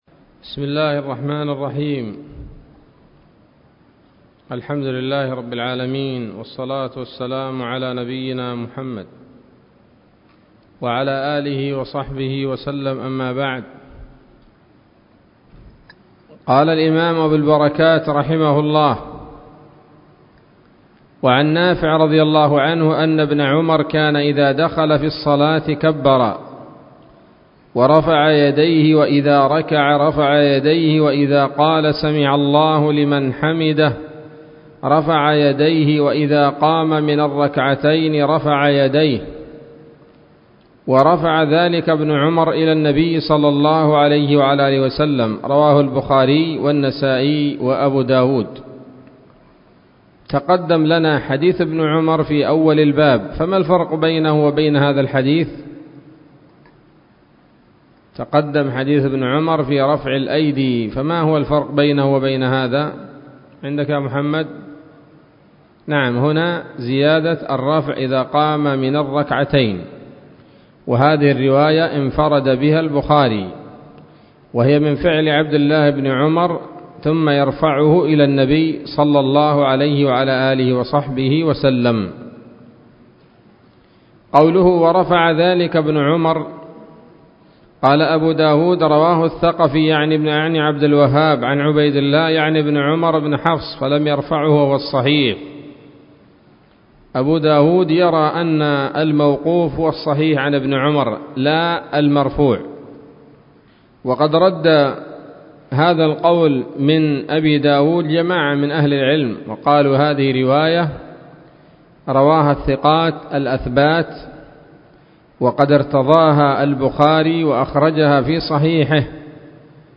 الدرس التاسع من أبواب صفة الصلاة من نيل الأوطار